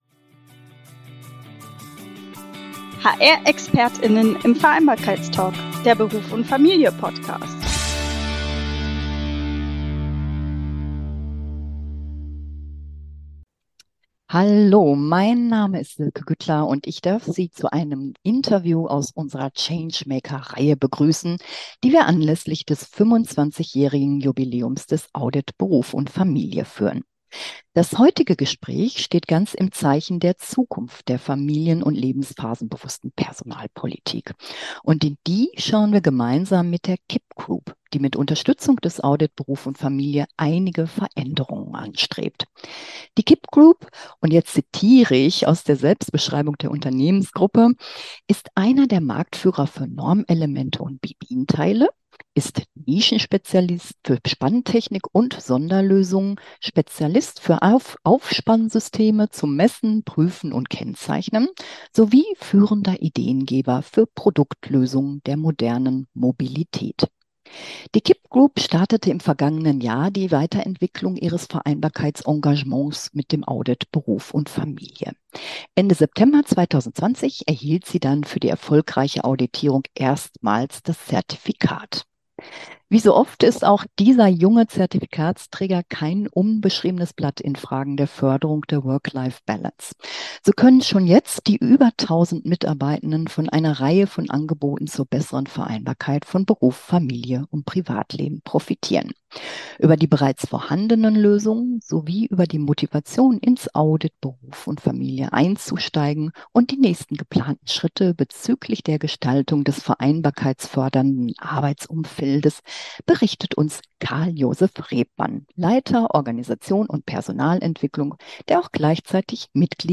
Beschreibung vor 2 Jahren In dieser akustischen Ausgabe unserer Change-Maker-Interviews geht es verstärkt um Veränderungen, die zukünftig mit Unterstützung der systematischen betrieblichen Vereinbarkeitspolitik bewirkt werden sollen.